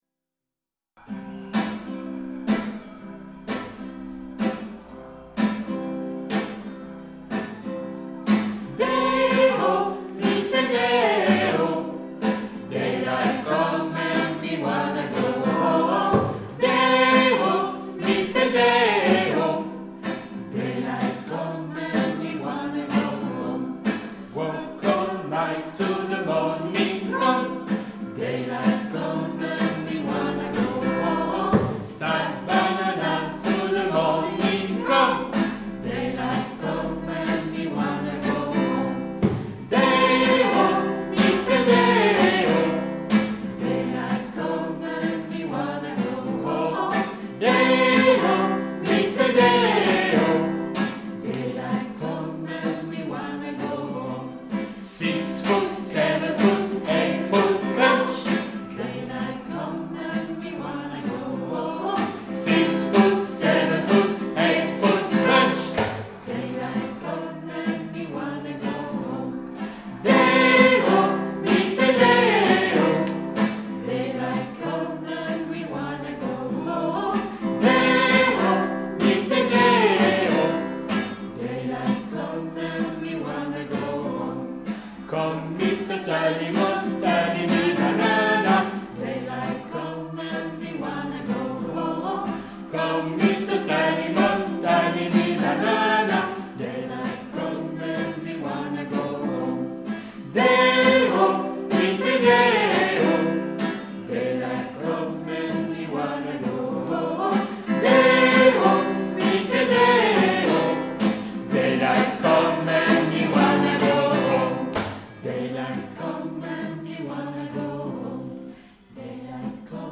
Day-O (The Banana Boat Song) est un mento jamaïcain.